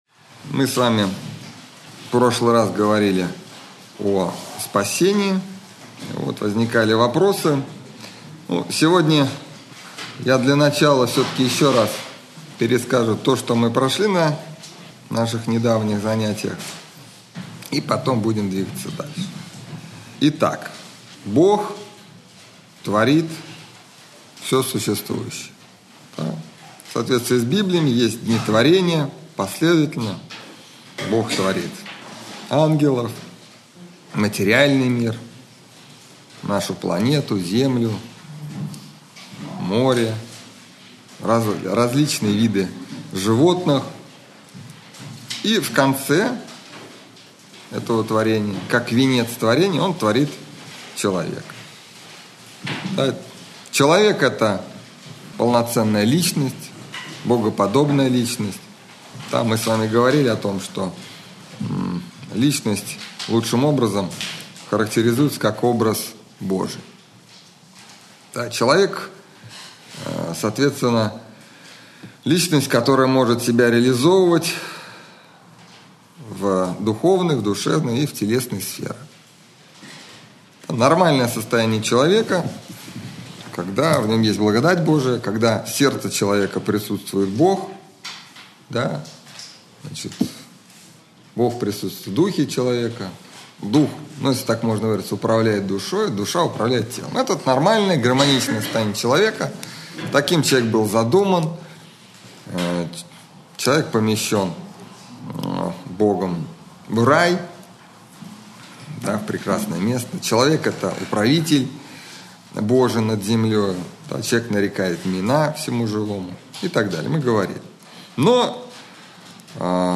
Общедоступный православный лекторий